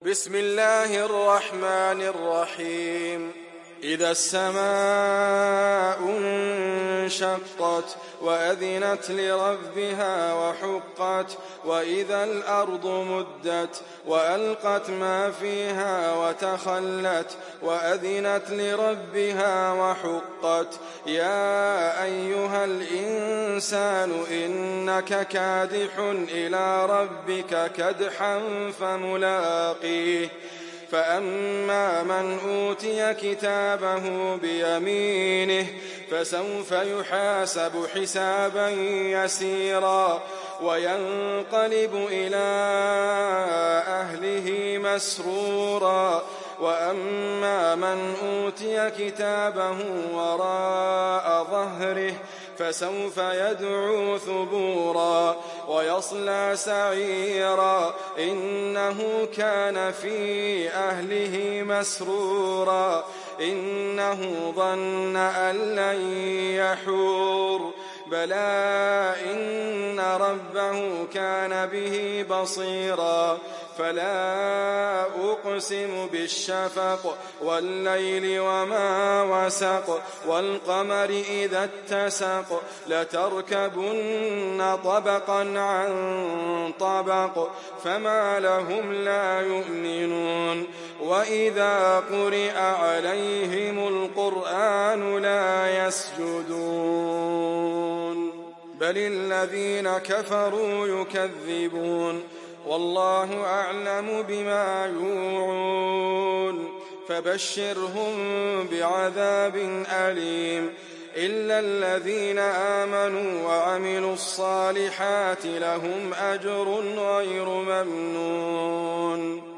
تحميل سورة الانشقاق mp3 بصوت إدريس أبكر برواية حفص عن عاصم, تحميل استماع القرآن الكريم على الجوال mp3 كاملا بروابط مباشرة وسريعة